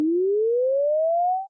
tone_up.ogg